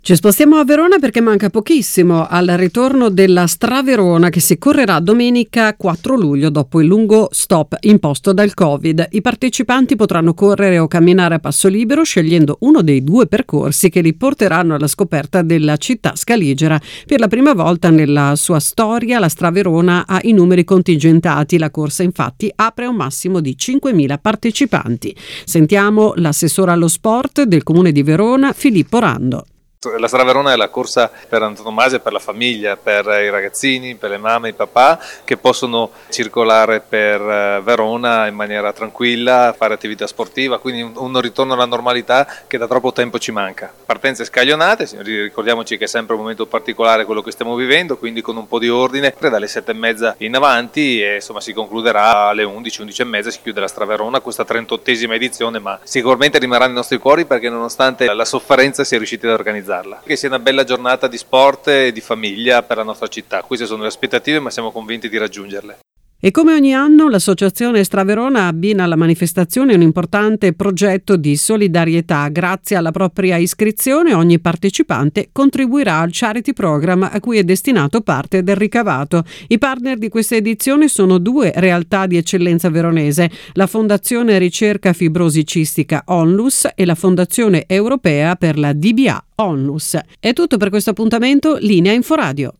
L’intervista all’assessore allo sport del Comune di Verona Filippo Rando: